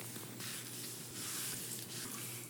mop.ogg